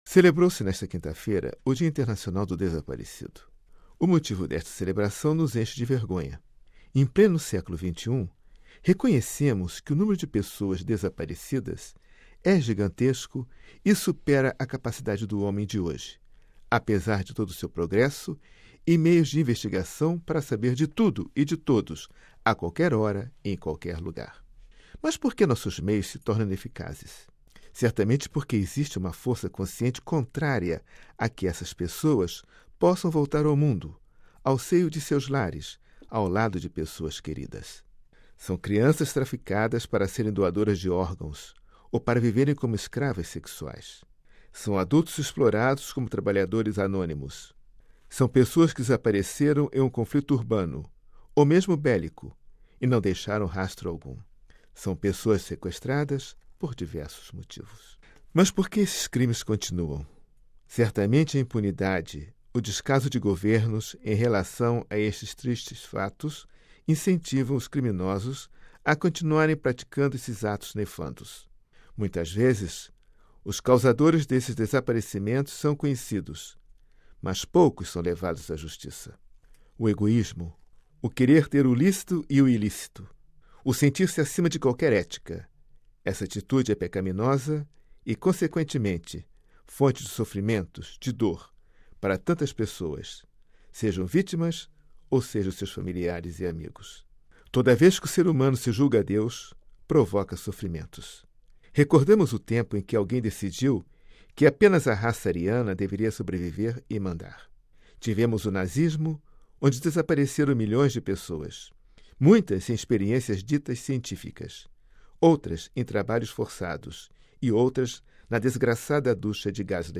Editorial: Dia Internacional do Desaparecido